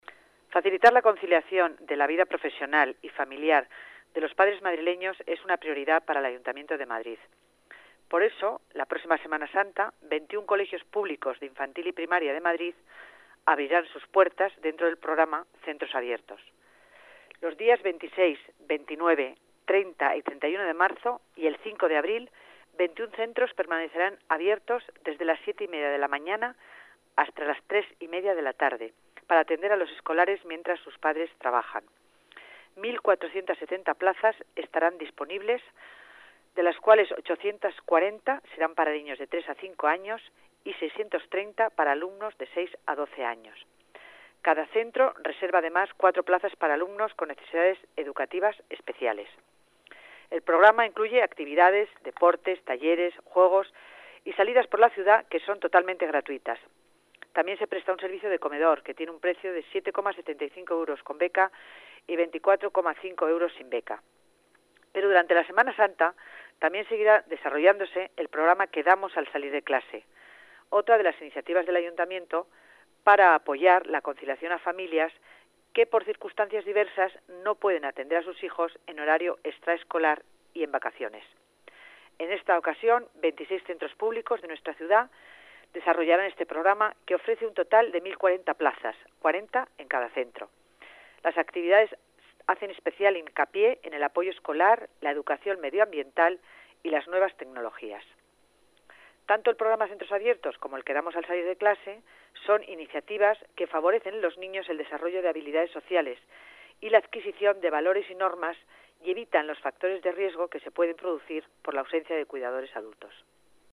Nueva ventana:La delegada de Familia y Servicios Sociales, Concepción Dancausa, habla del programa Centros Abiertos de Semana Santa